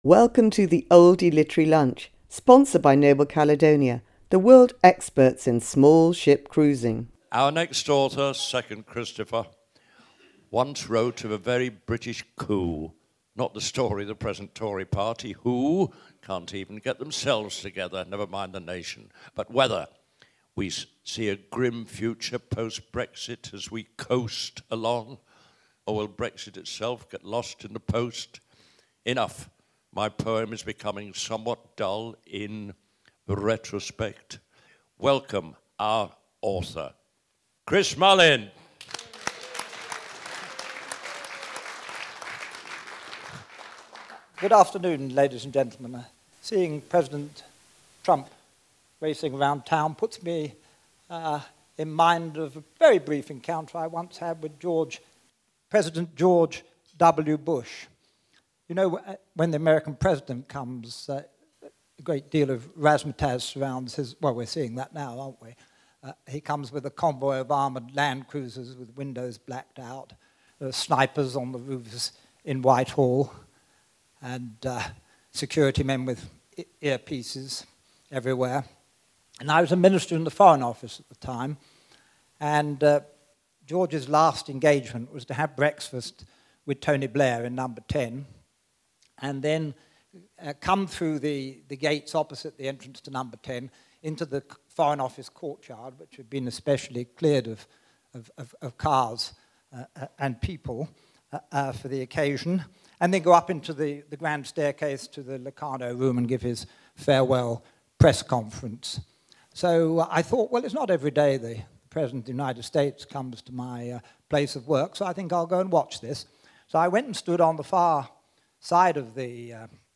Introduced by Barry Cryer, former MP Chris Mullin speaks at the Oldie's June lunch about an encounter with George W Bush, the story behind his bestselling hit, A Very British Coup, and why he wrote a sequel to it.